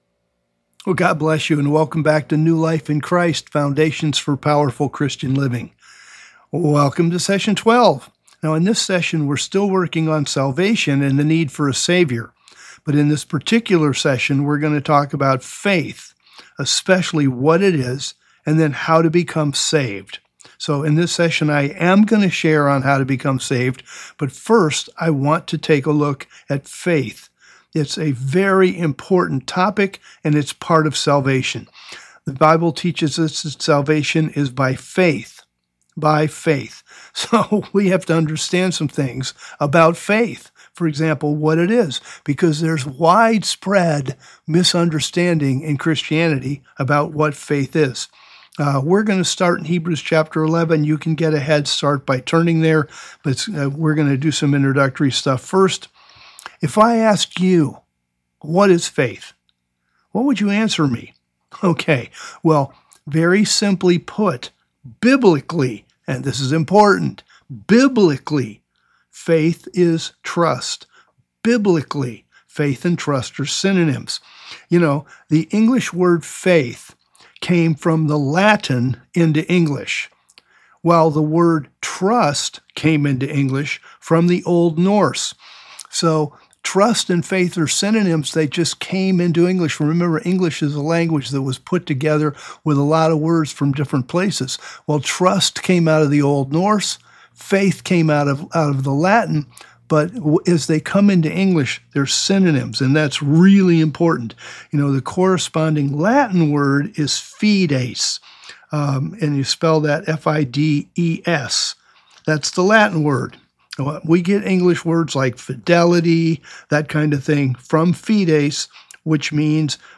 The teaching continues with discussion about the concepts of grace and works, crucial elements which differentiate how the Old and New Testaments address salvation and righteousness.